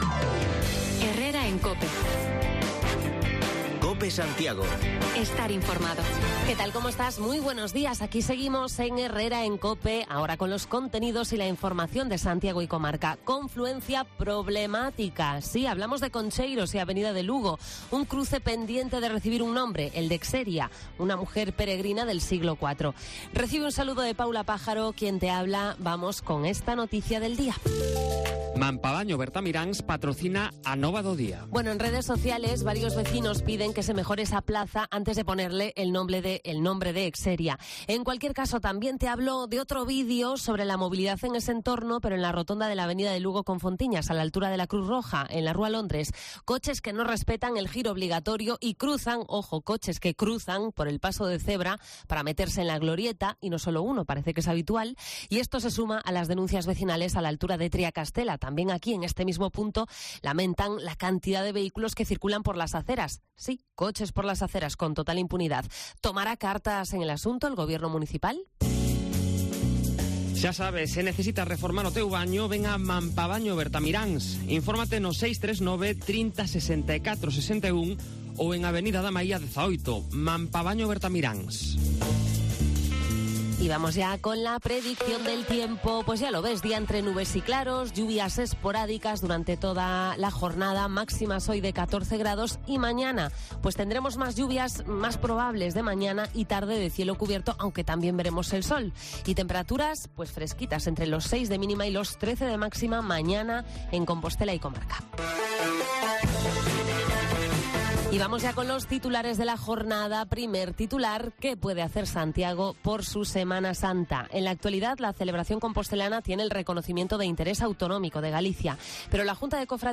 Actualizamos lo más destacado de esta jornada, con parada en el barrio compostelano de Fontiñas para pulsar la opinión del vecindario sobre circulación y aparcamientos